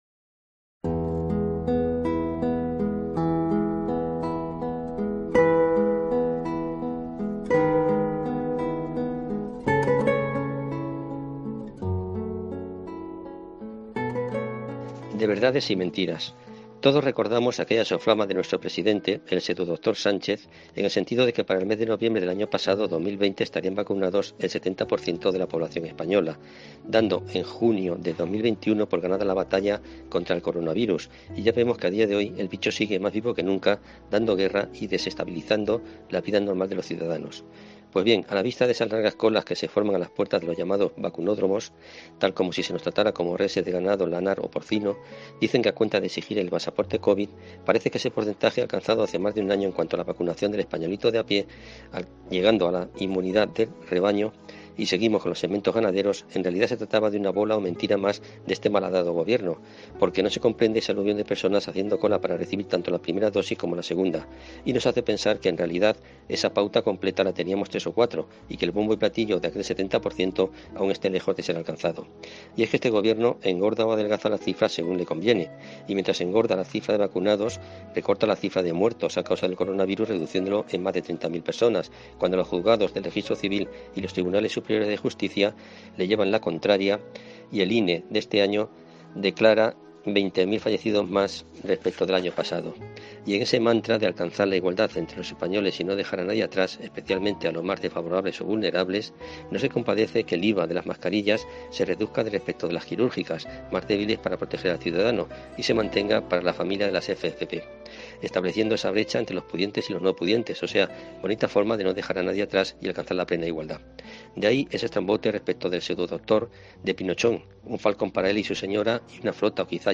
columna radiofónica semanal